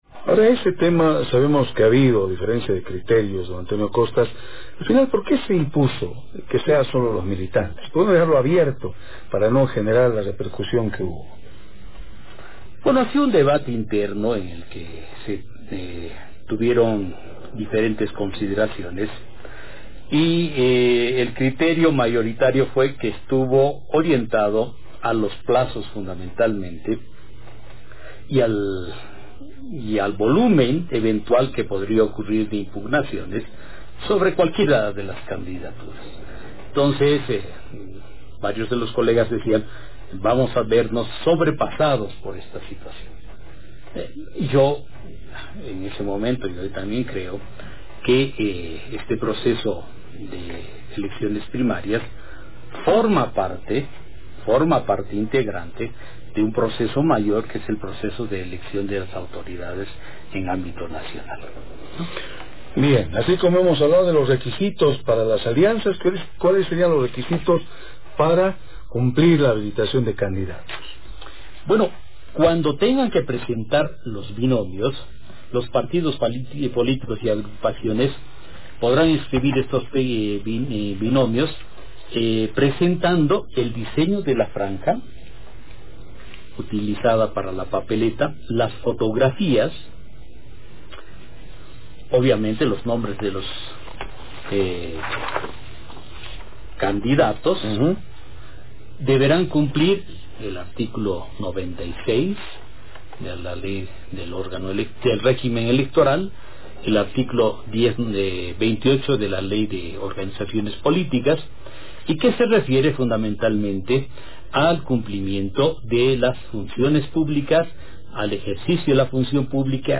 El Vicepresidente del Tribunal Supremo Electoral (TSE), Antonio Costas Sitic, explica el procedimiento y los requisitos para la solicitud para el registro de alianzas políticas para las Elecciones Primarias del 27 de enero de 2019.